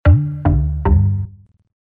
SMS_lub_MMS_2.mp3